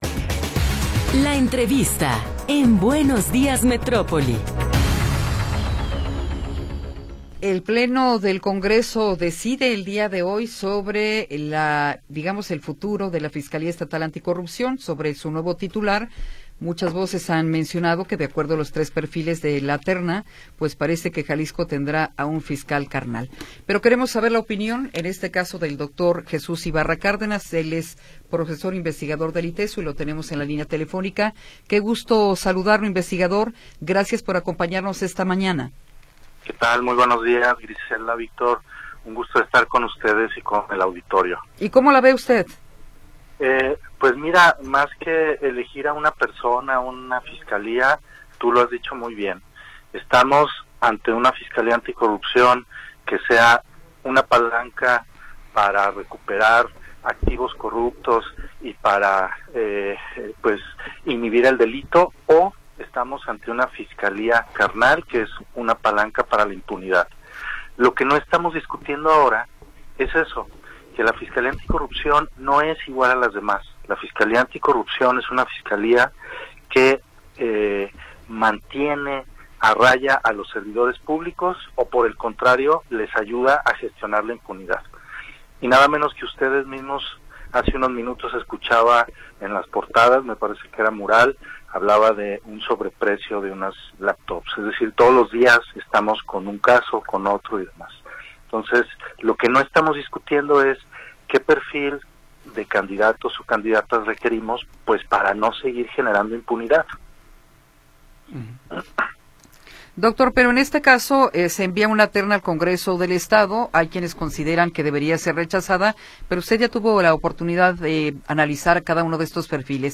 En: Entrevistas